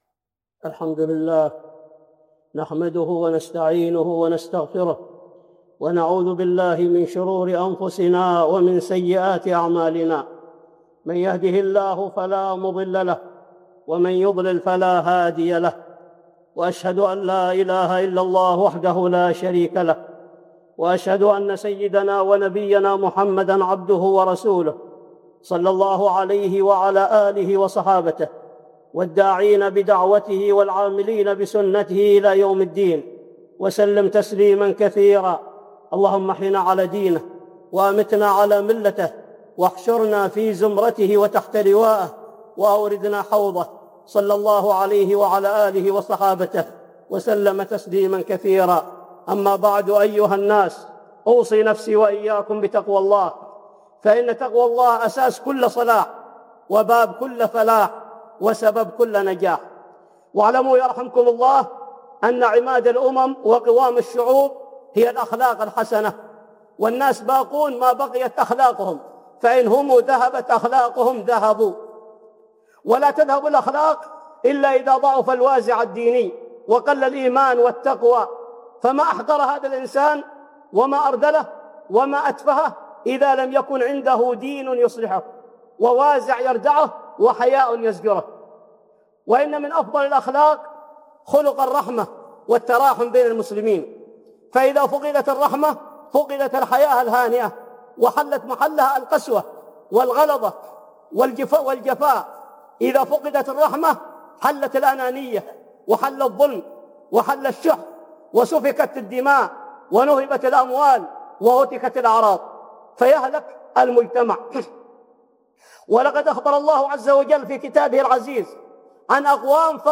(خطبة جمعة) الرحمة